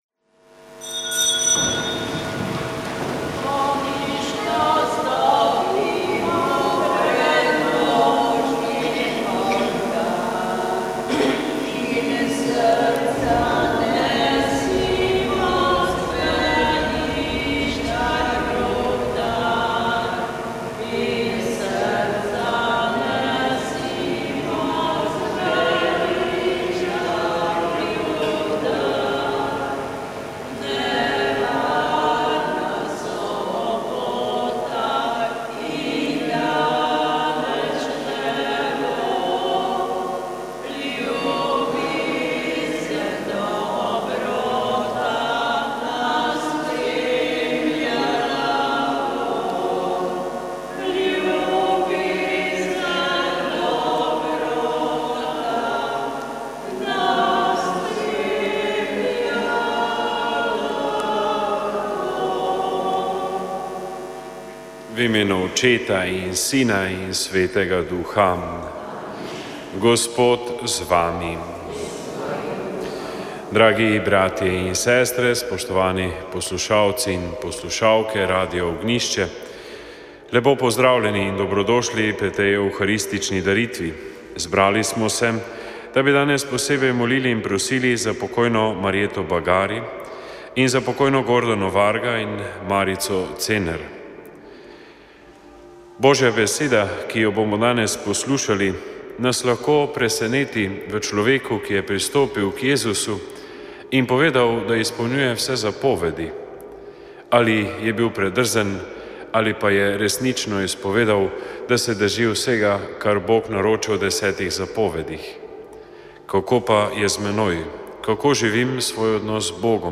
Sv. maša iz stolne cerkve sv. Nikolaja v Murski Soboti 1. 3.